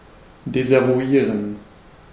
Ääntäminen
IPA: /dɛsavuˈiːʁən/